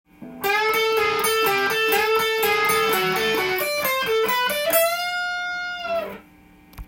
すべてのフレーズがDまたはD7で使えます。
３弦のチョーキングをしながら全音チョーキング、半音チョーキング、戻す（ダウンチョーキング）